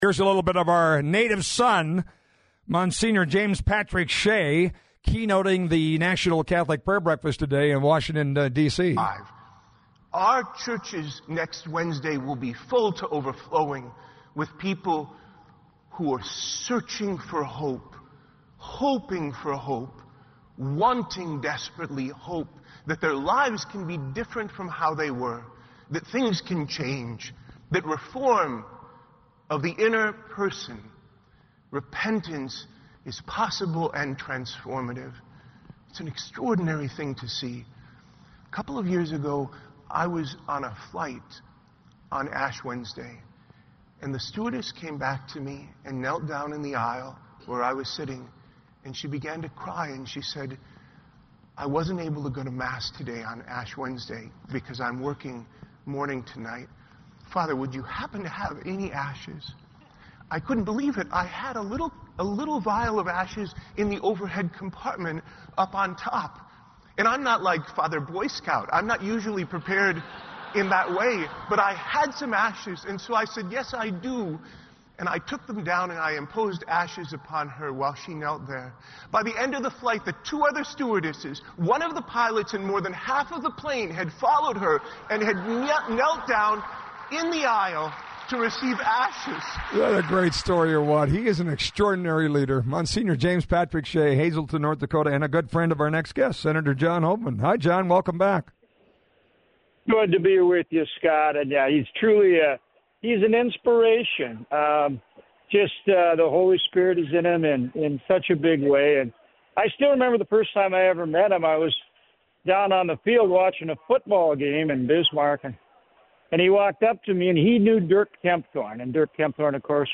hoeven-interview-2-28.mp3